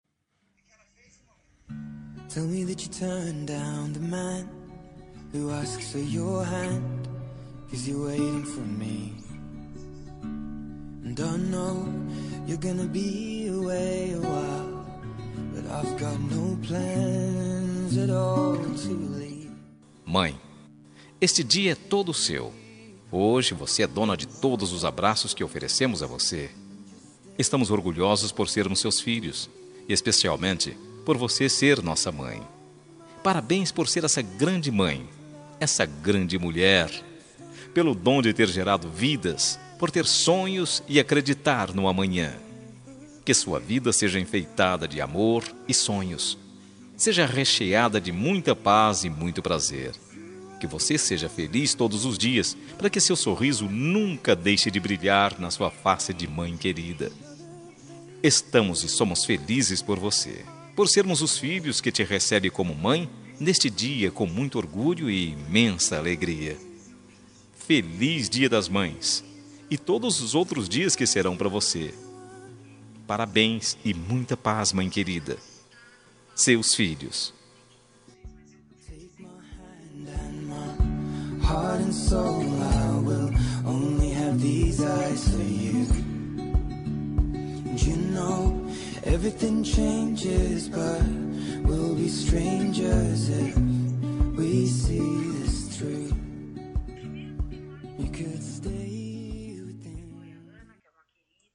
Dia das Mães – Para minha Mãe – Voz Masculina – Plural – Cód: 6528